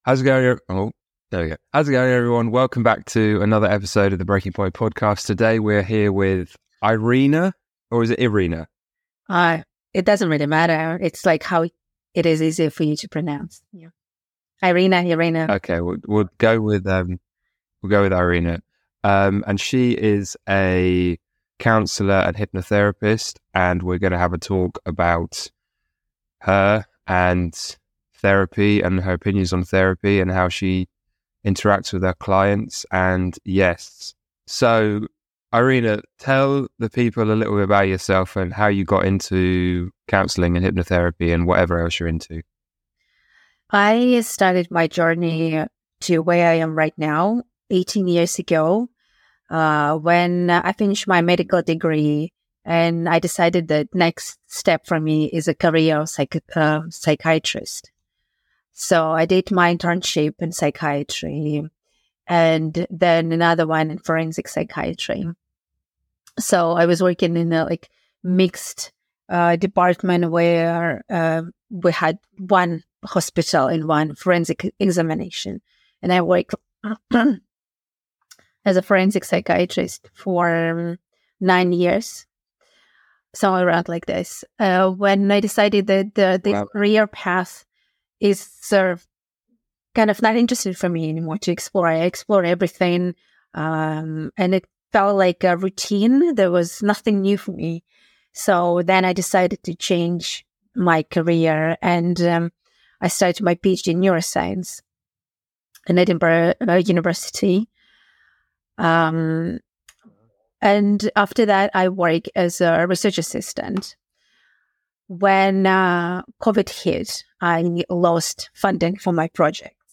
a hypnotherapist and trauma specialist. We discussed how to facilitate the healing process from a traumatic event, how our childhood experiences shape us, and the human need to make sense of a situation in order to process it fully.